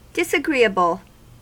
Ääntäminen
IPA : [dɪsəˈɡɹiəbəɫ]